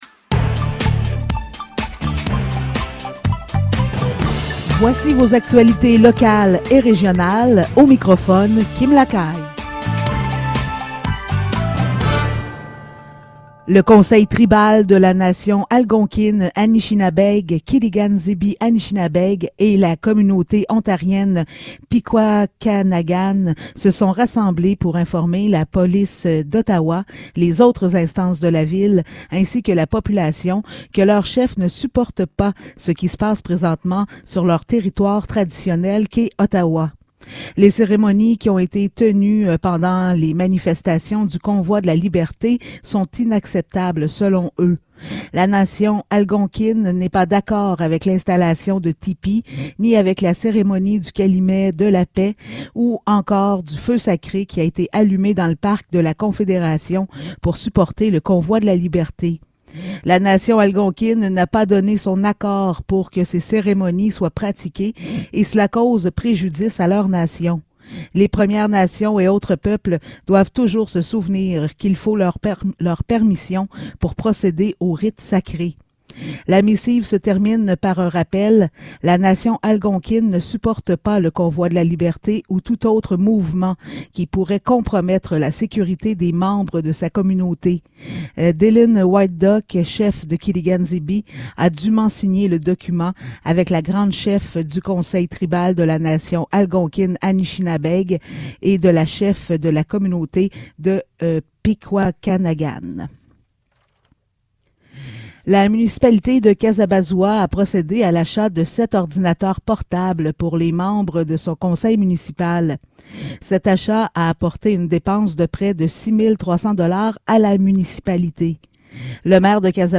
Nouvelles locales - 3 février 2022 - 15 h